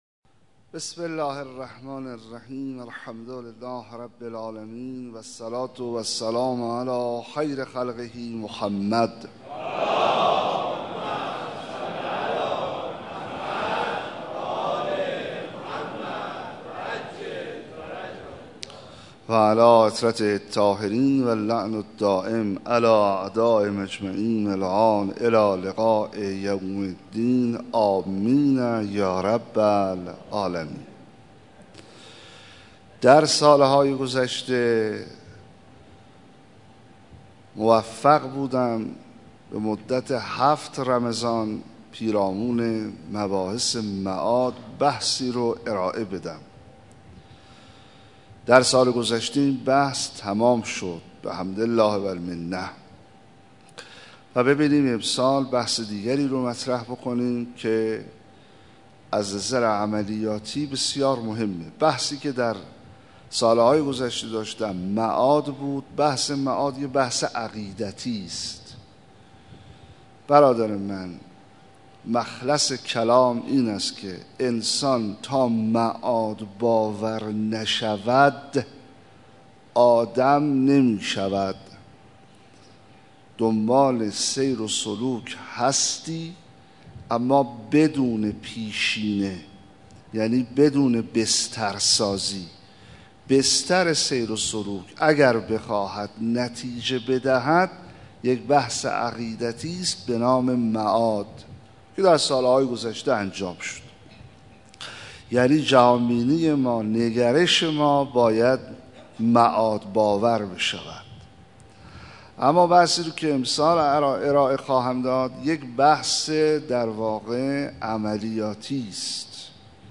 سخنرانی
روز دوم رمضان93/مسجد امیر